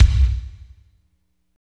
29.01 KICK.wav